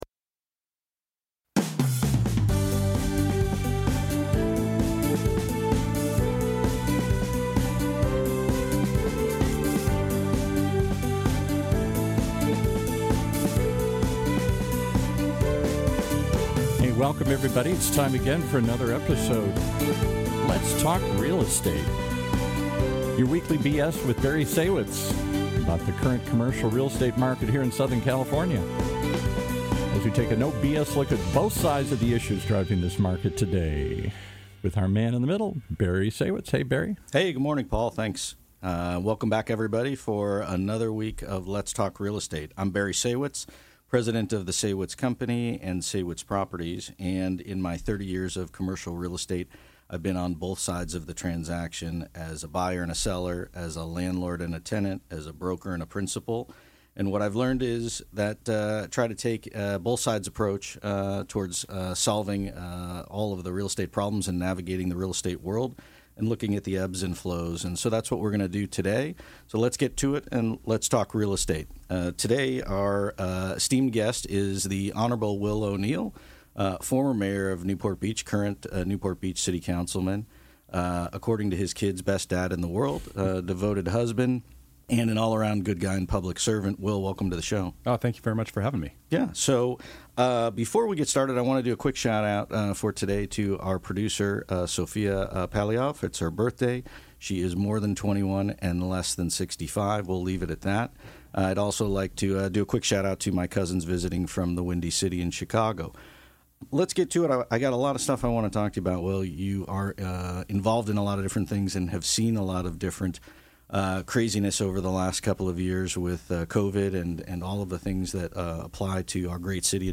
Will O‘Neill, former Mayor of Newport Beach Joins Us To Discuss Growth & Development in NPB